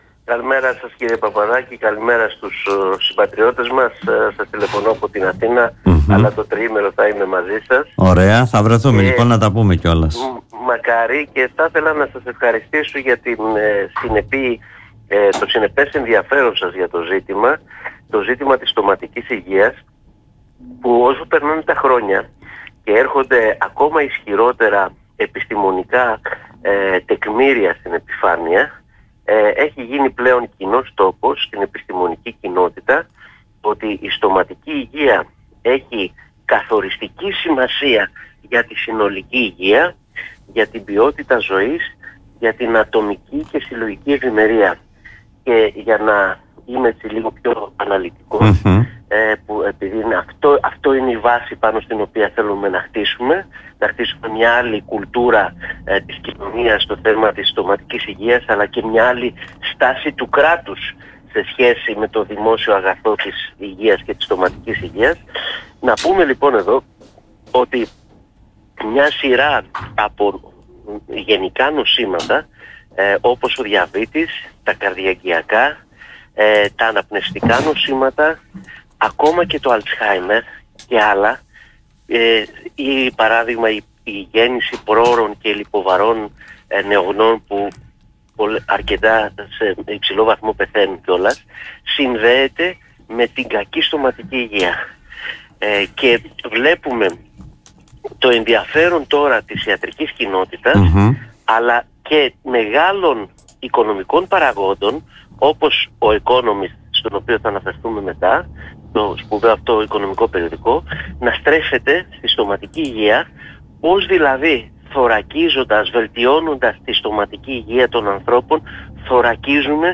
μίλησε στον politica 89.8 και στην εκπομπή “Δημοσίως”